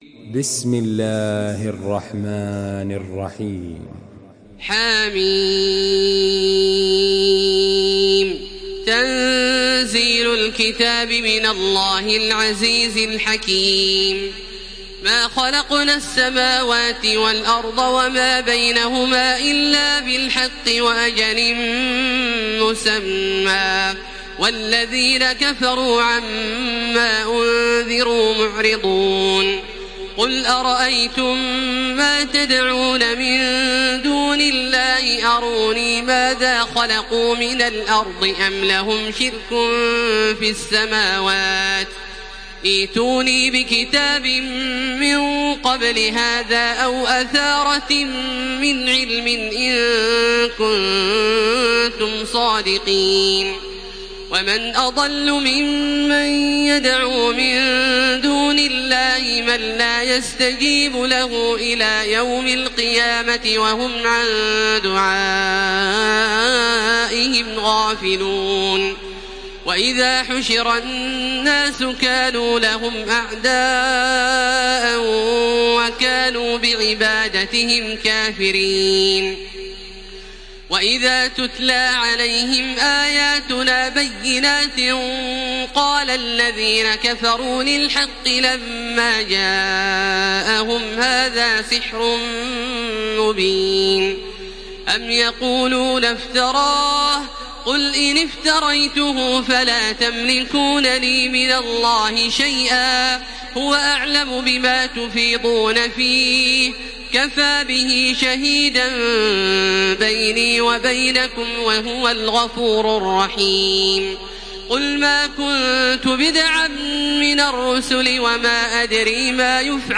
Surah Ahkaf MP3 by Makkah Taraweeh 1433 in Hafs An Asim narration.
Murattal